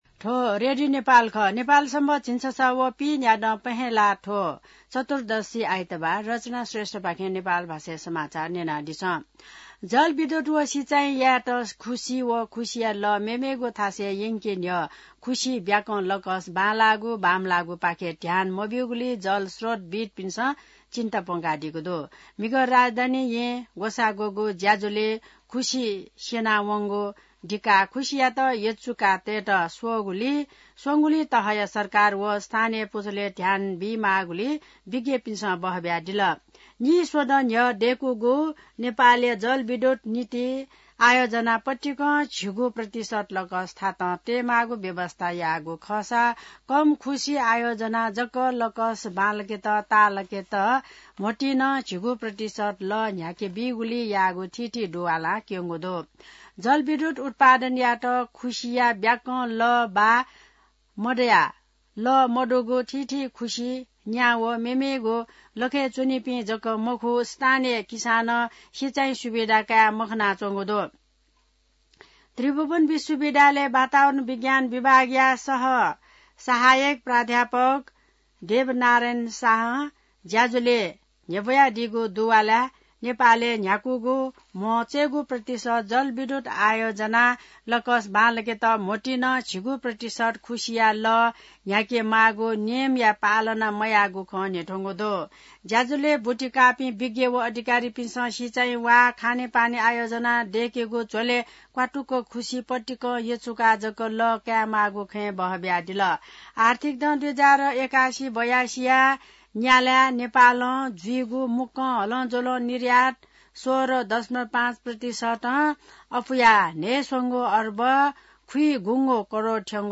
नेपाल भाषामा समाचार : २९ पुष , २०८१